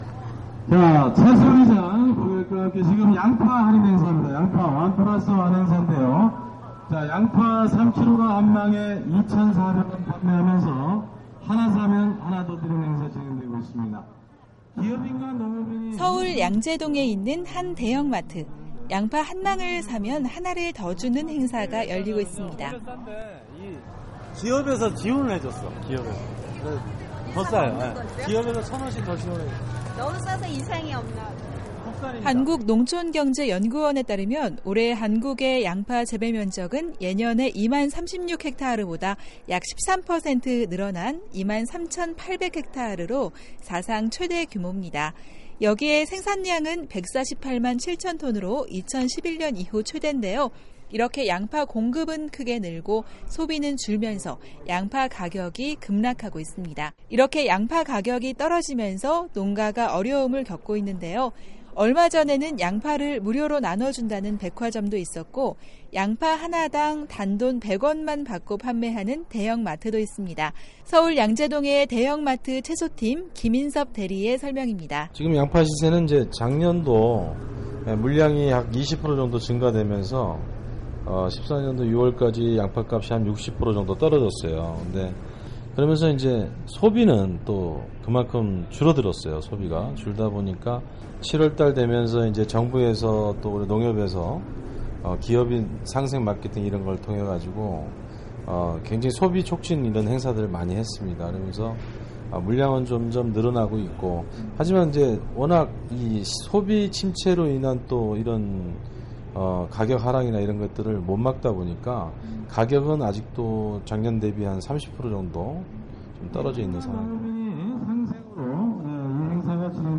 양파판매행사 현장에서